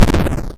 snd_death.mp3